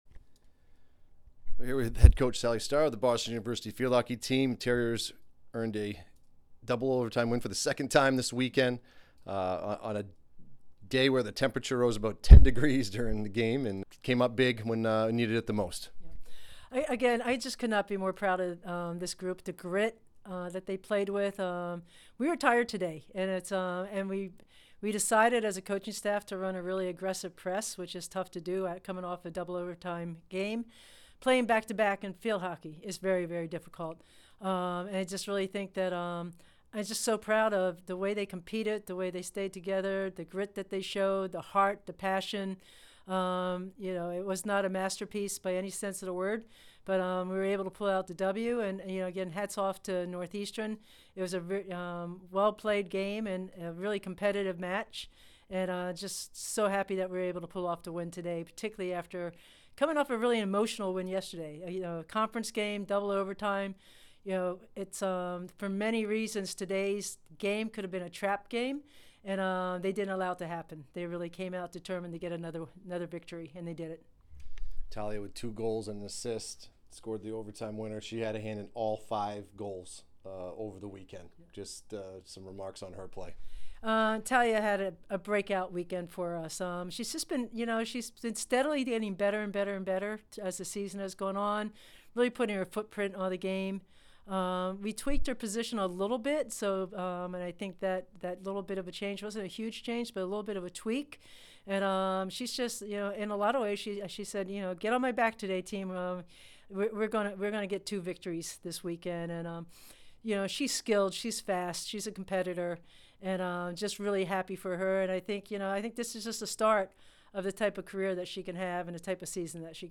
Field Hockey / Northeastern Postgame Interview